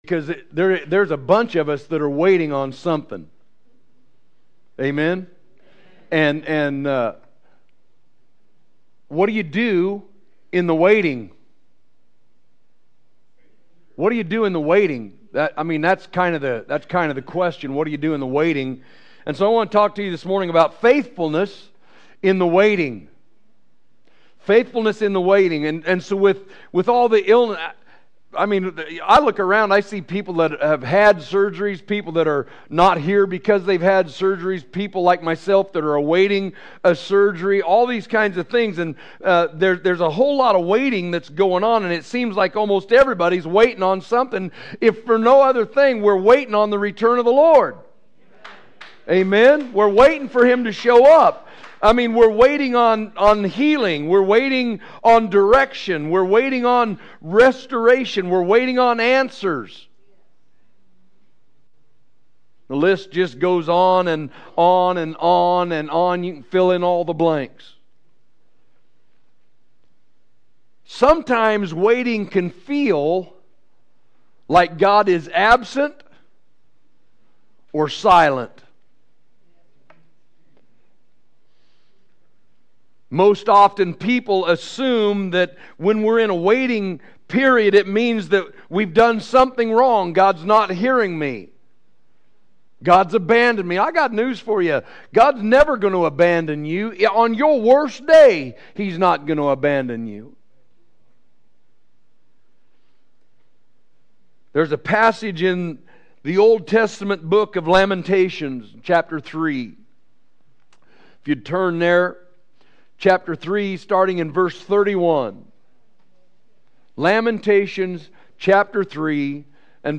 Sermons – Harvest Christian Assembly
Category: Sermons